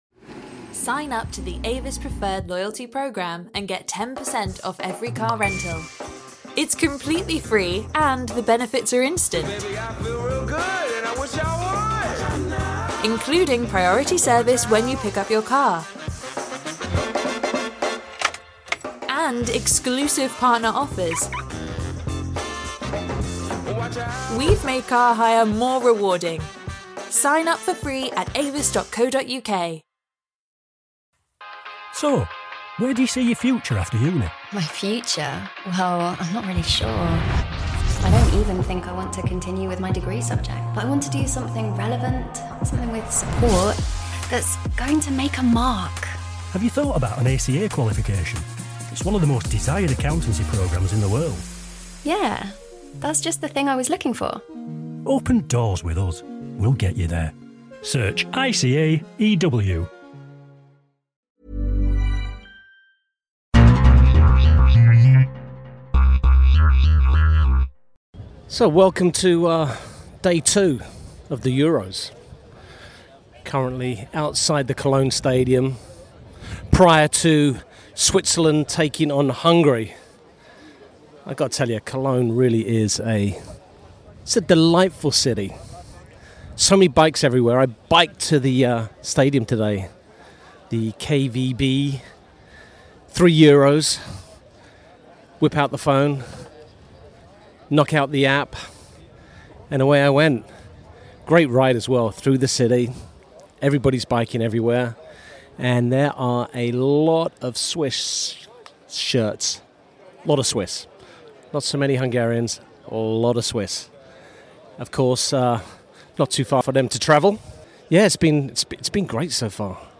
Clive Tyldesley interview at Euro 2024